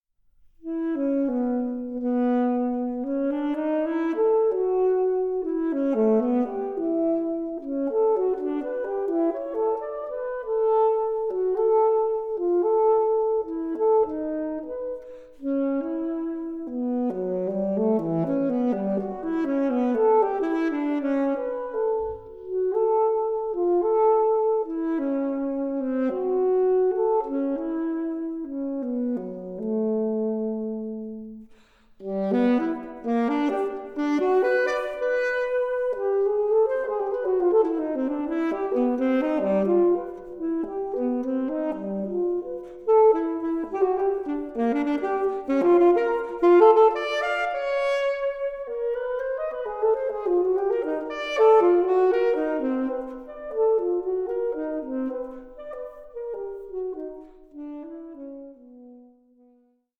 SOLO SAXOPHONE
Saxophonist